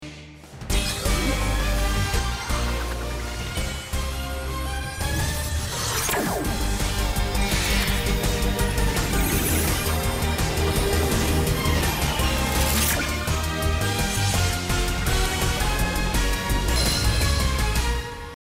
Трансформация под музыку без слов